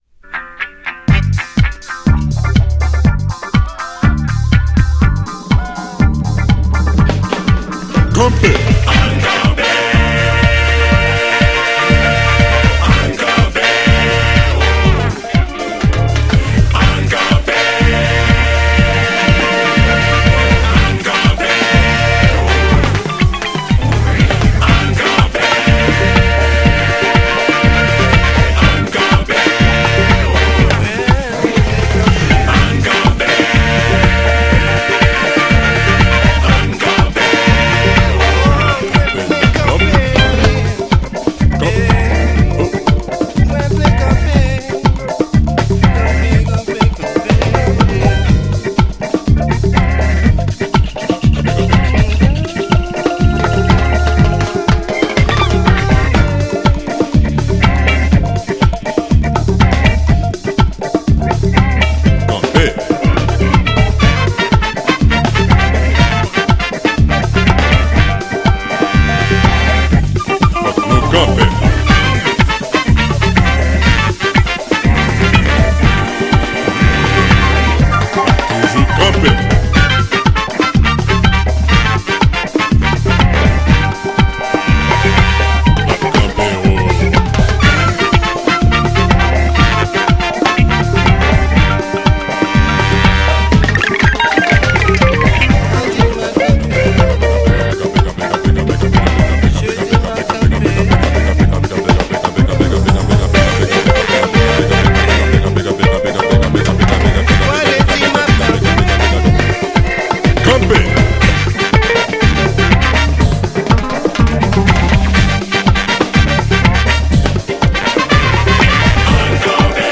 HOUSEというジャンルだけでは括れない傑作!!
GENRE House
BPM 121〜125BPM